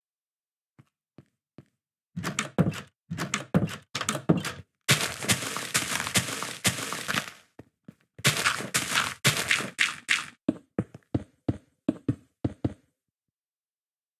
Just toggle HRTF in the sound options and hear the difference - there is a difference in the sound when you click on the UI in 1.20.1, but no difference in 1.20.2.{*}{*}
HRTF turned off (1.20.1).m4a